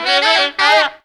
FUNKY GROOVE.wav